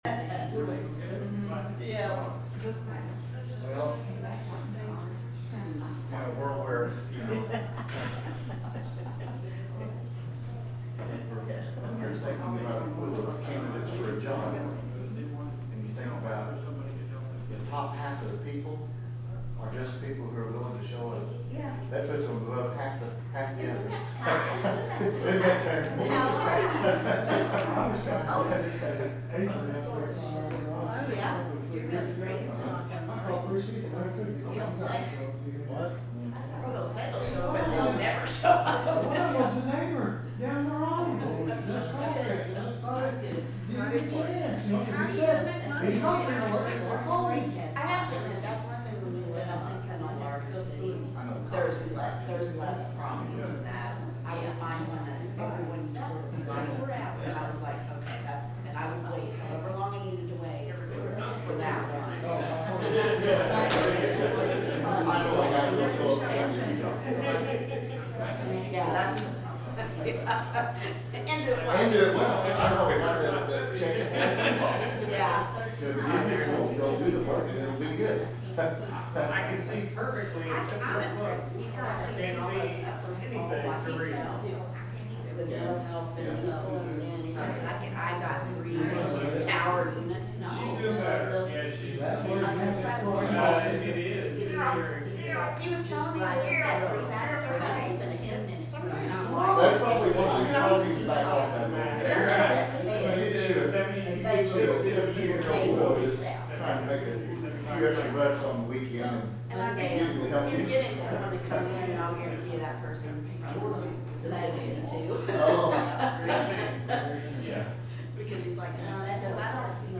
The sermon is from our live stream on 7/13/2025